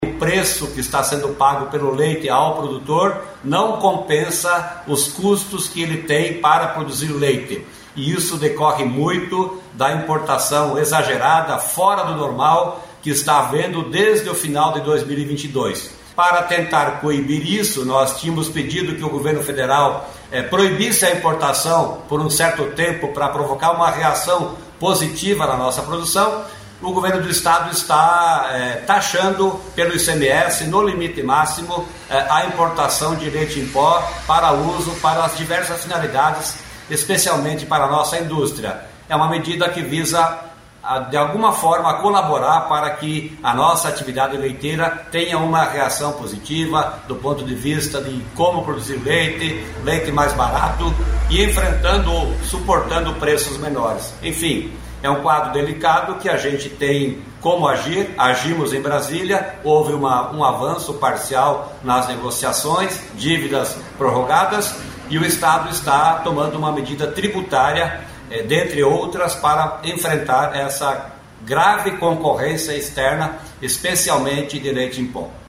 Sonora do secretário da Agricultura e do Abastecimento, Norberto Ortigara, sobre as duas medidas tomadas para proteger os produtores de laticínios do Estado | Governo do Estado do Paraná